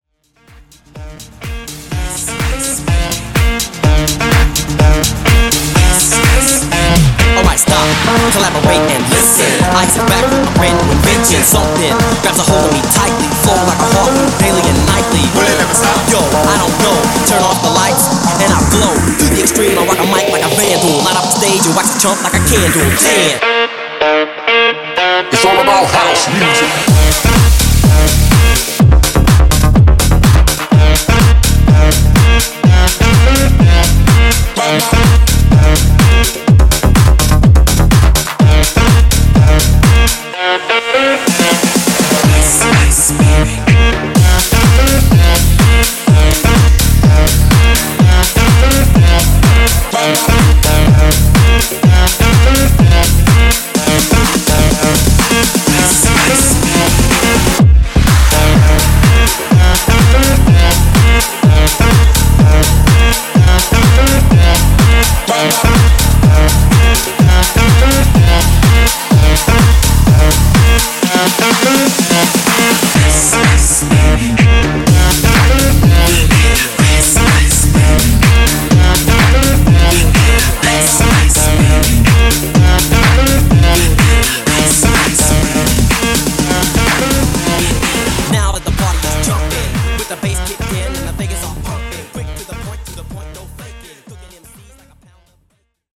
Genre: 80's
Clean BPM: 123 Time